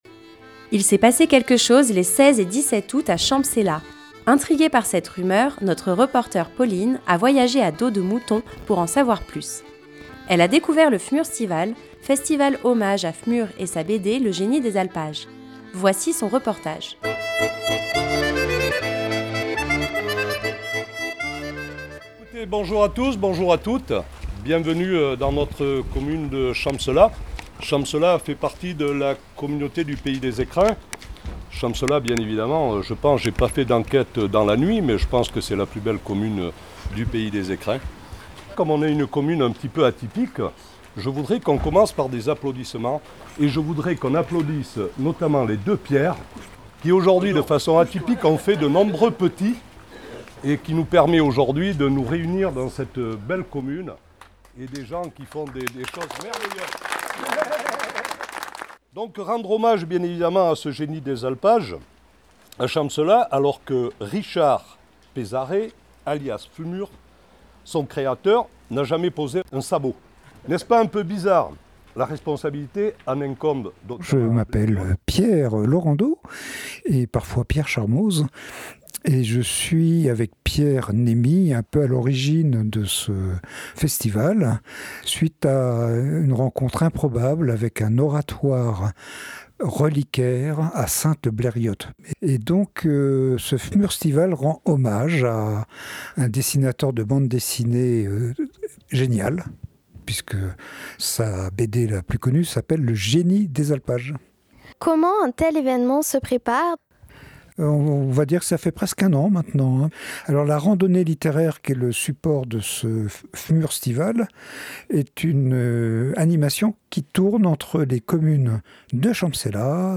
Elle a découvert le F'murrr'stival, festival en hommage à F'murrr et sa BD Le Génie des Alpages. Voici son reportage.